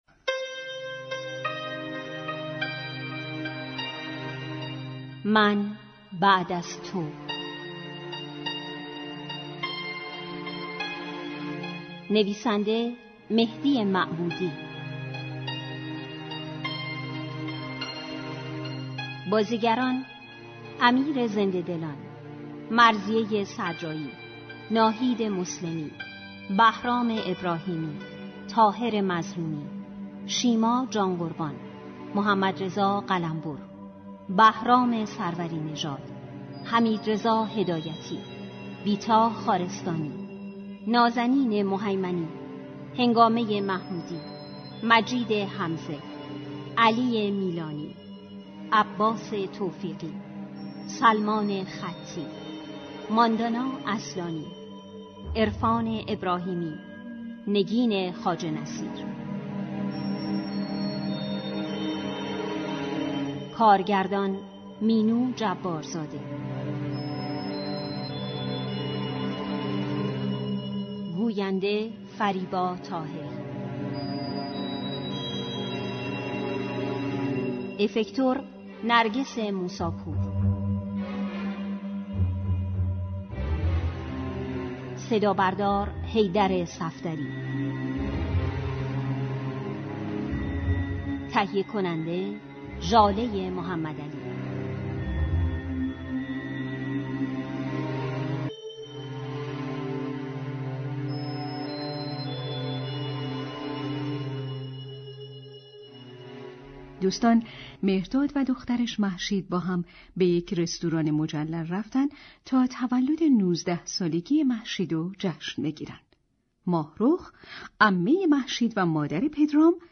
سه شنبه نهم مرداد ماه ، شنونده نمایش رادیویی